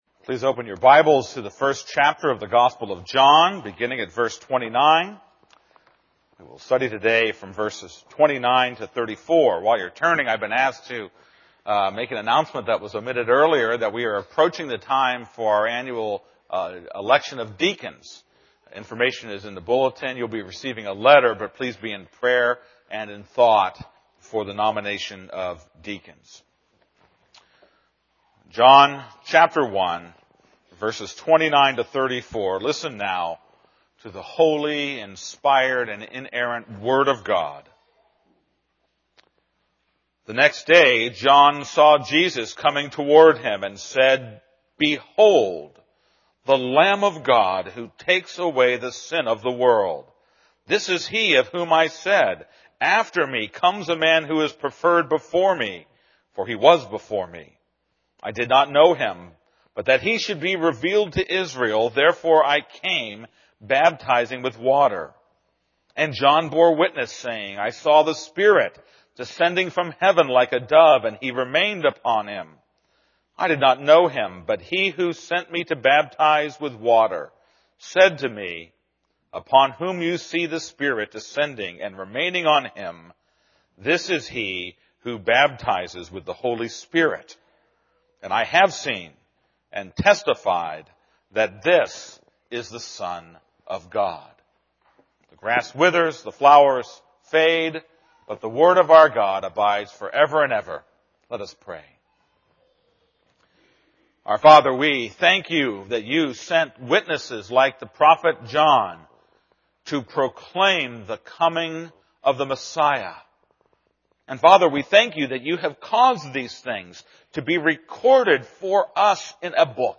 This is a sermon on John 1:29-34.